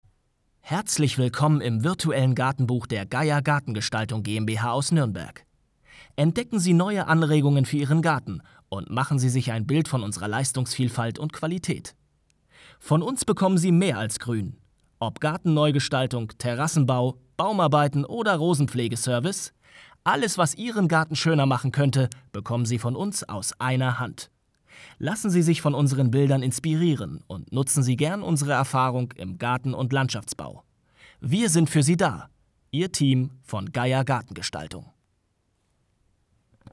klare, sanfte und ausdrucksstarke Stimme mit Wiedererkennungswert / auch Trickstimme
Sprechprobe: eLearning (Muttersprache):